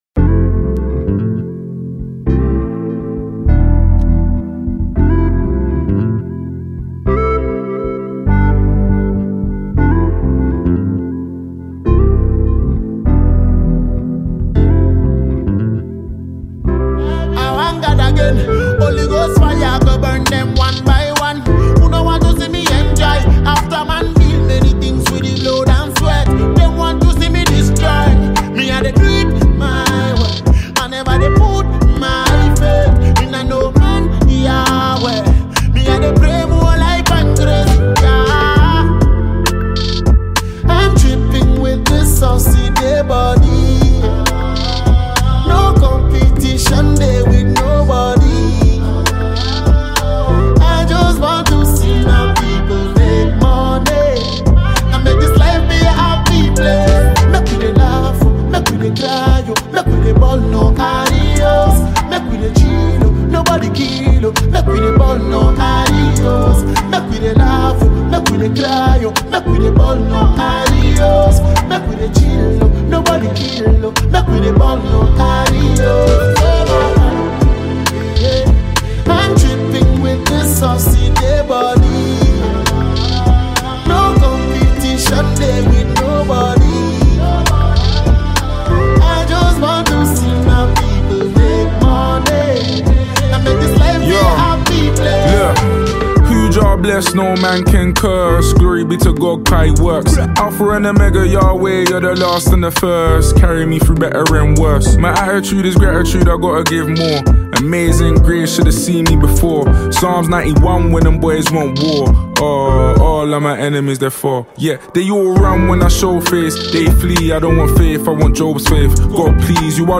smooth delivery